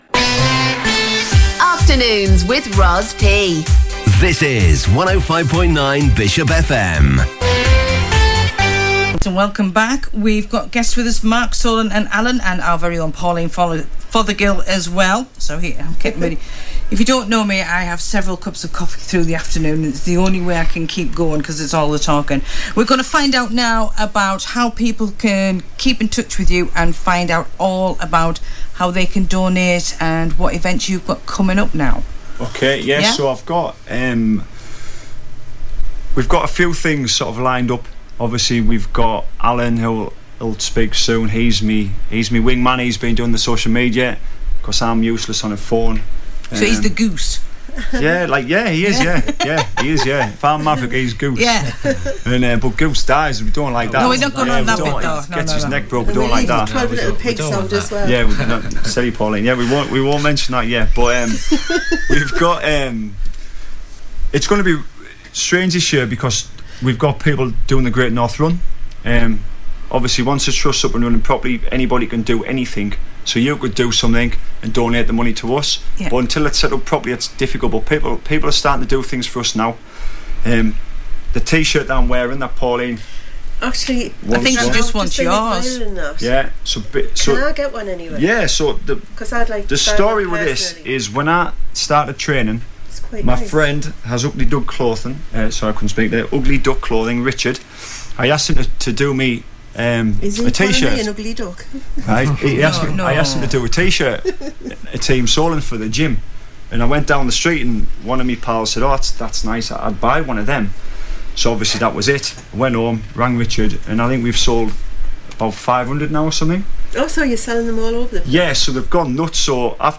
interview regarding his fundraising for Cancer support and his story